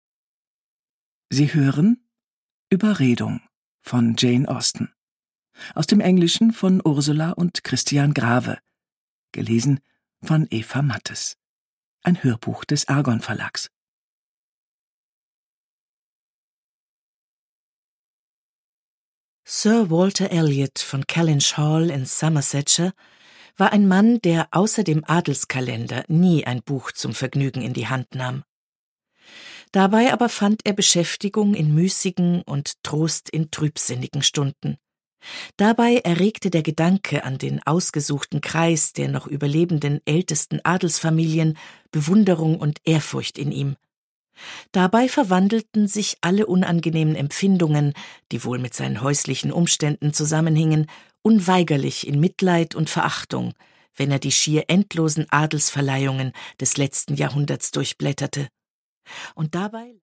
Produkttyp: Hörbuch-Download
Gelesen von: Eva Mattes